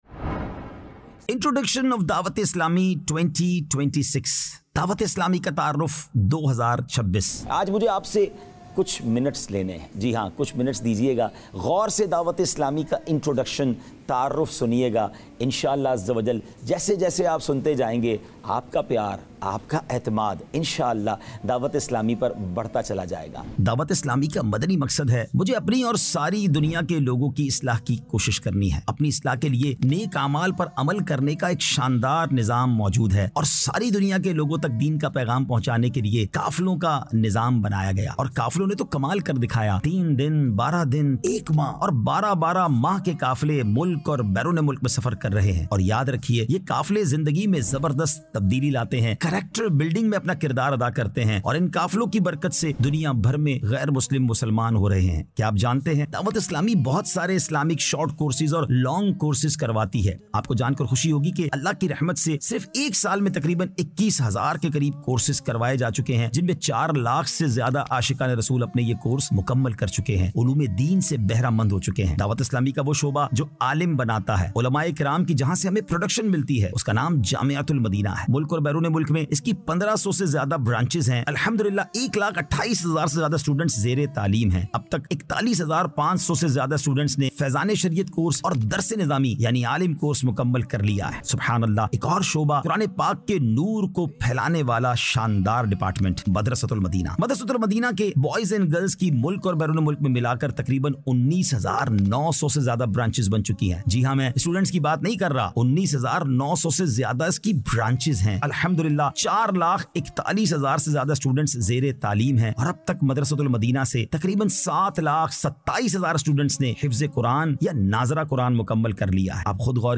Introduction of Dawateislami | 06 Minutes Animated Documentary 2026